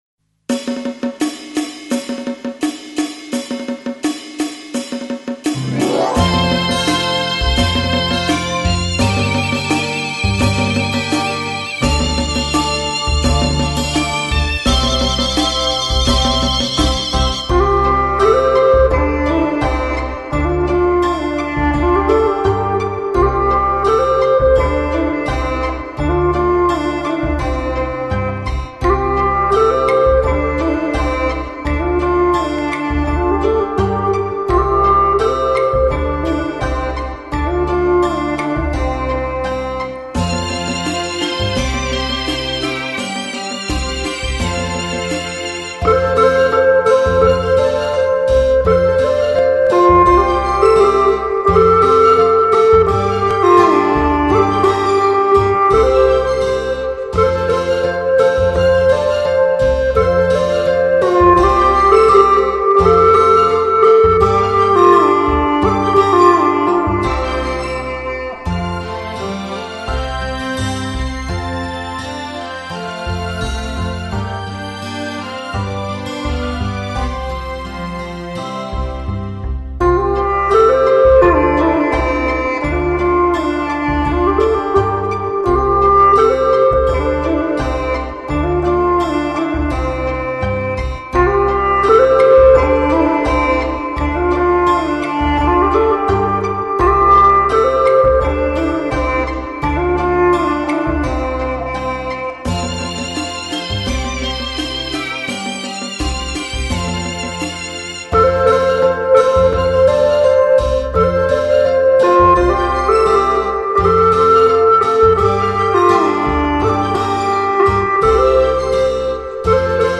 音色优美动听，低音区浑厚；中音区圆润柔美，高音区明亮，在民族乐队中已成为富有表现力的色彩乐器，
这两张CD是用巴乌对一些我们熟悉曲目的重新演绎。
不高不低的音乐，适合现在在听音乐的你我！